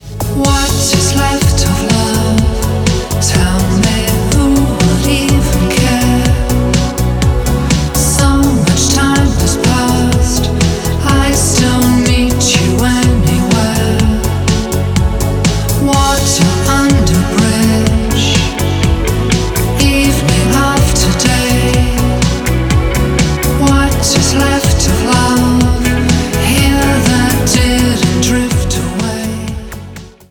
• Качество: 256, Stereo
мужской вокал
мелодичные
Synth Pop
лирические
синти-поп